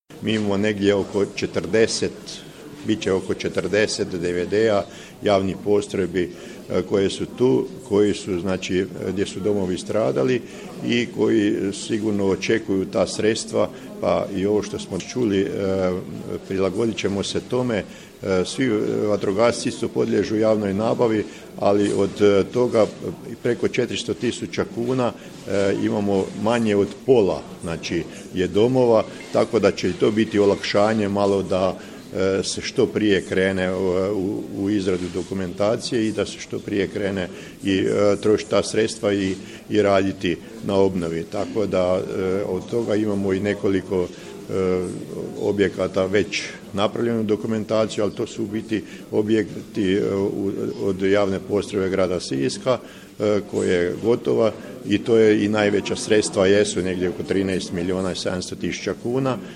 U petak, 16. prosinca 2022. godine, u prostoru DVD-a Desna Martinska Ves predstavljen je Javni poziv za dodjelu bespovratnih sredstava za obnovu objekata koje koriste javne vatrogasne postrojbe i dobrovoljna vatrogasna društva na potresom pogođenom području.